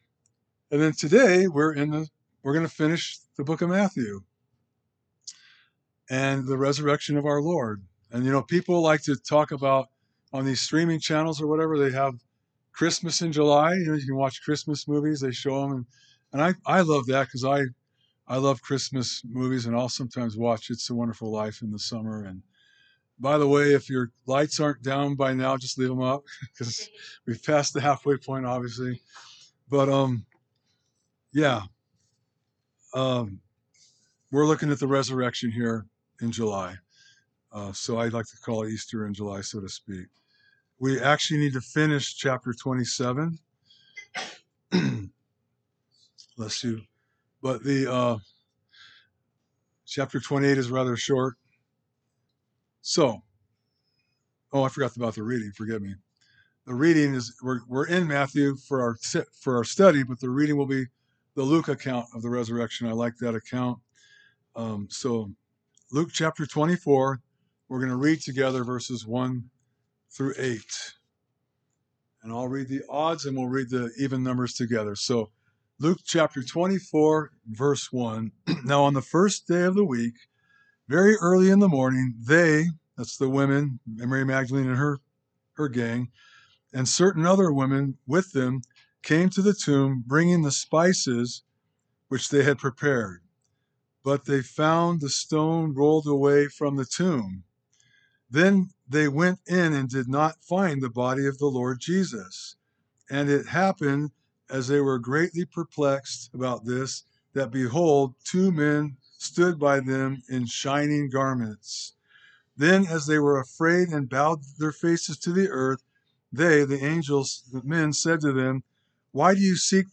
Sermons - Calvary Chapel Ames